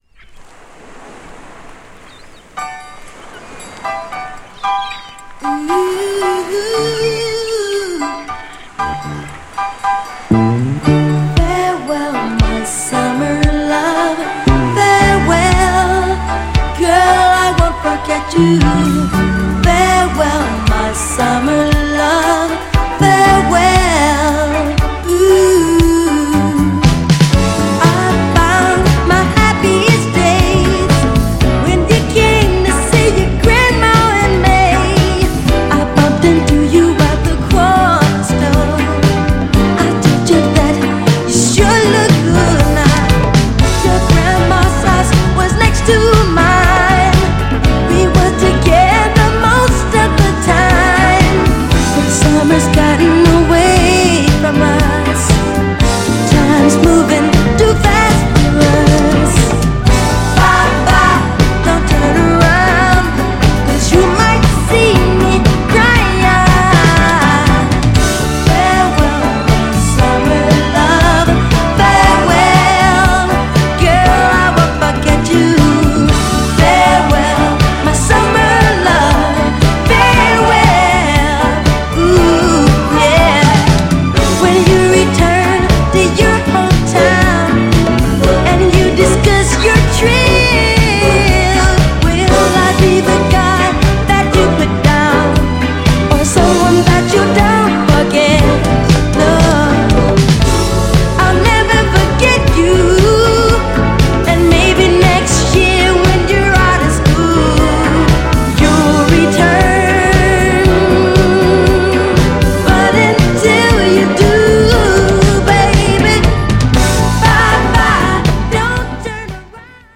GENRE Dance Classic
BPM 96〜100BPM
# POP # キャッチー # ソウル # メロウ # 泣き